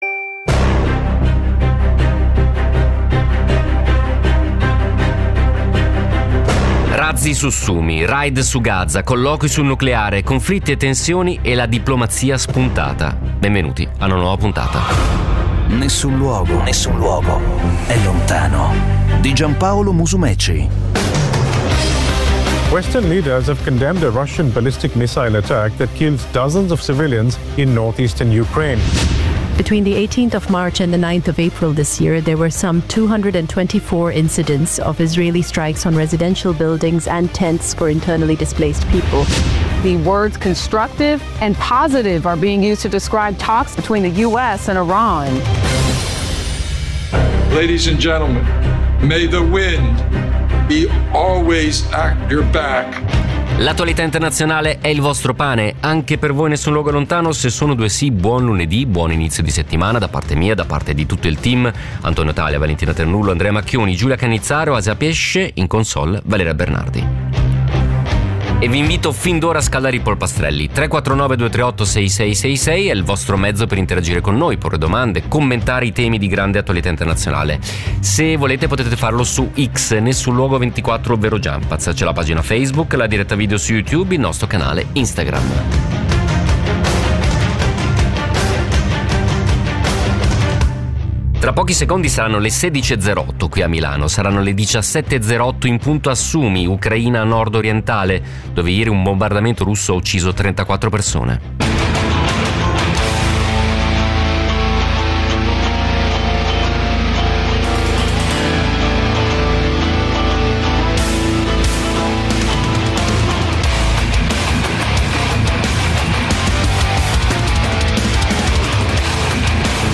… continue reading 780 epizódok # News Talk # Notizie # Radio 24